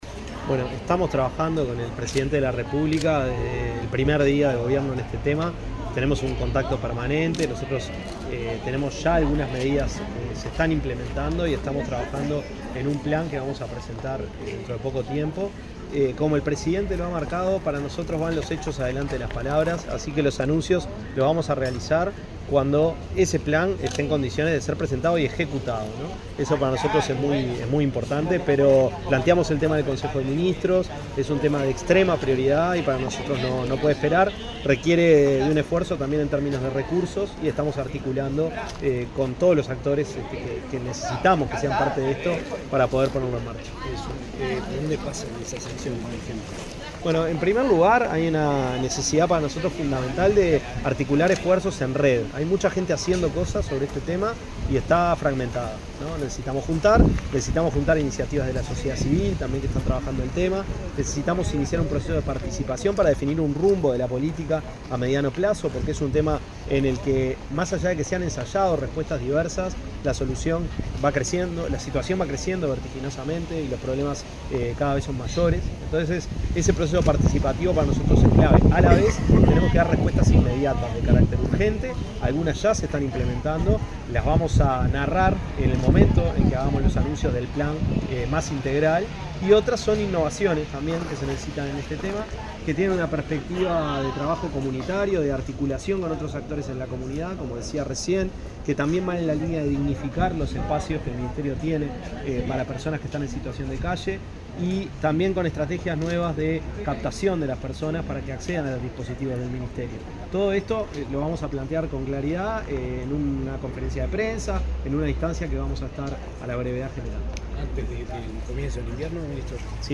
Declaraciones del ministro de Desarrollo Social, Gonzalo Civila
El ministro de Desarrollo Social, Gonzalo Civila, dialogó con la prensa en Canelones, durante su visita a tres modelos diferentes del Sistema Nacional